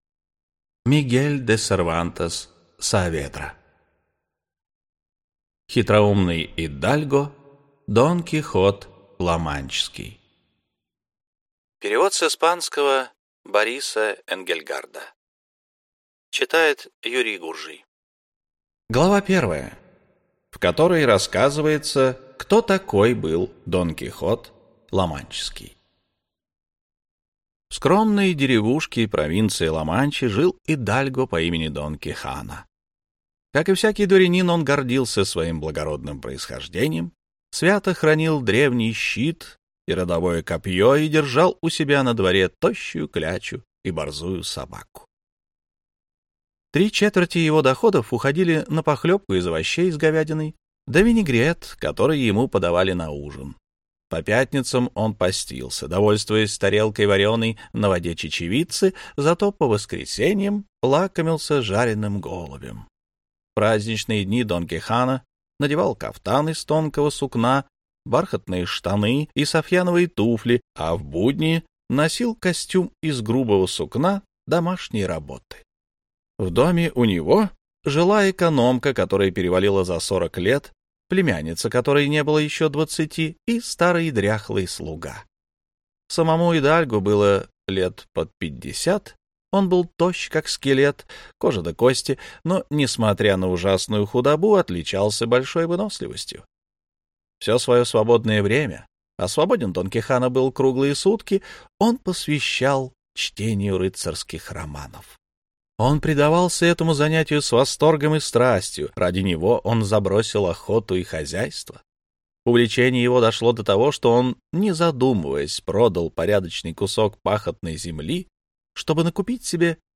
Аудиокнига Хитроумный идальго Дон Кихот Ламанчский. Часть 1 | Библиотека аудиокниг